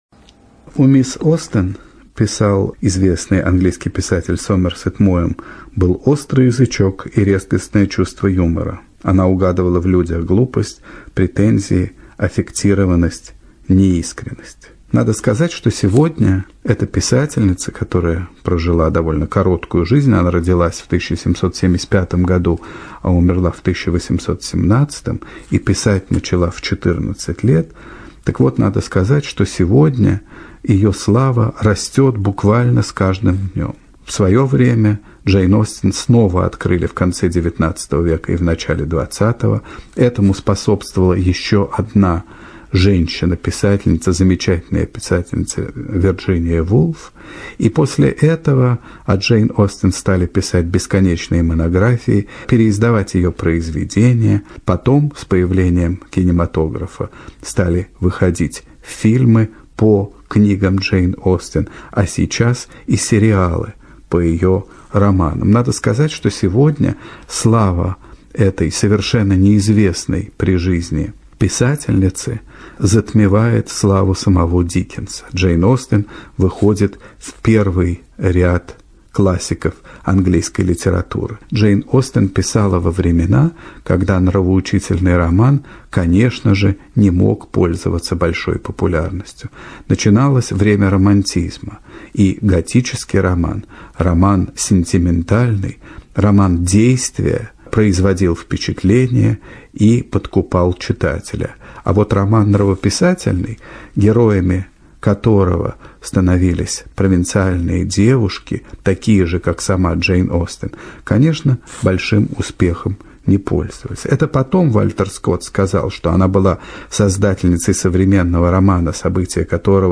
ЧитаетЧурсина Л.